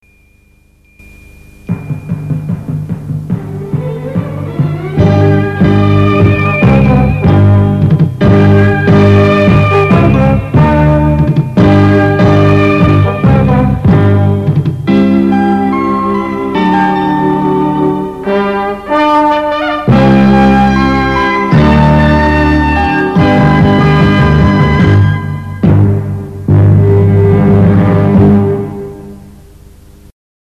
Sintonia d'inici d'emissions